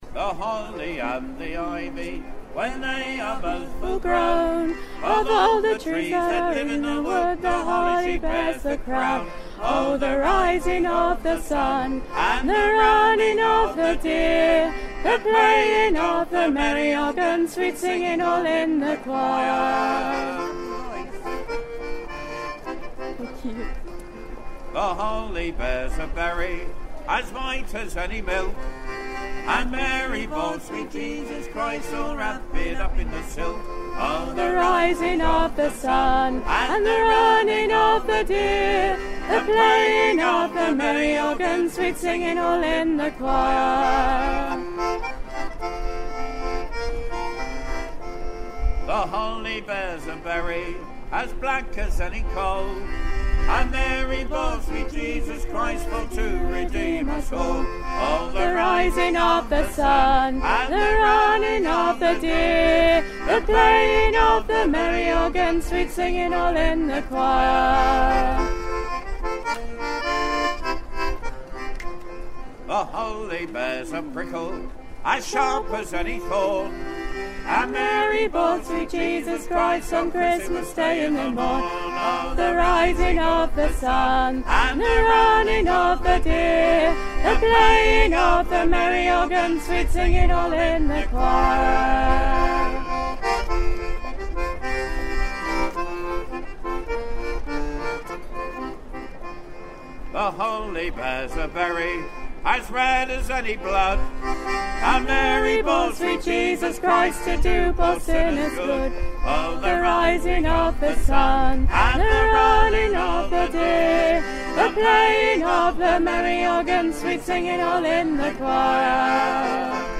The Holly and the Ivy folkv ersion